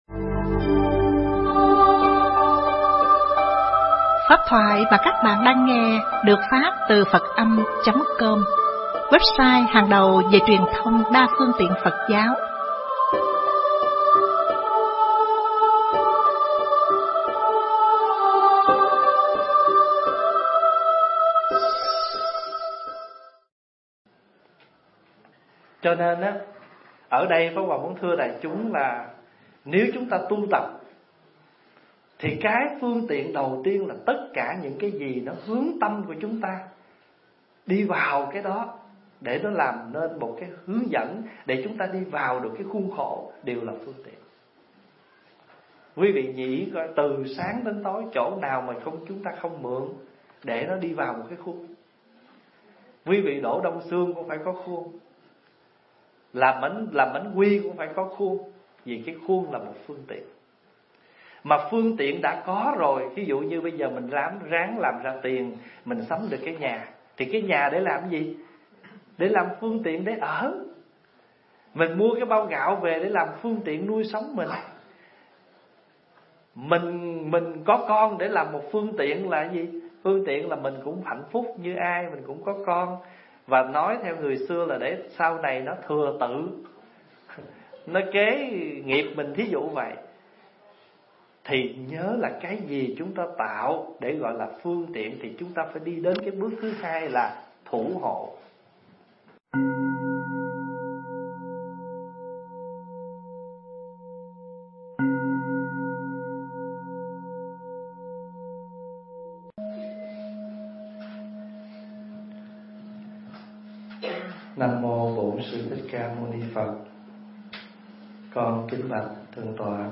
Nghe Mp3 thuyết pháp Phương Tiện và Thủ Hộ
thuyết giảng tại Chùa Văn Thù (Canada)